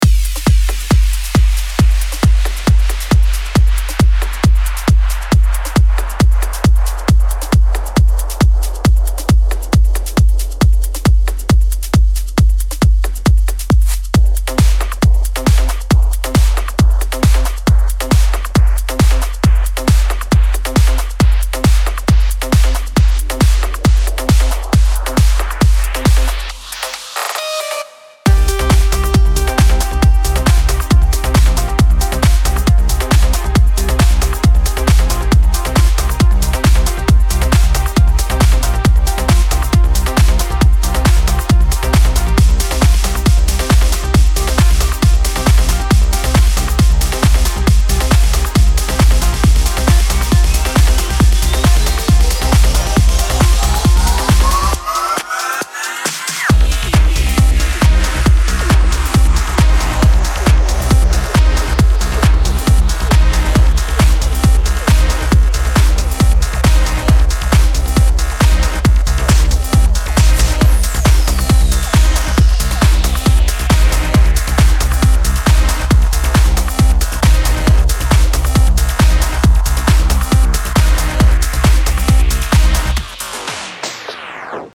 Прошу оценить сведение (Progressive trance)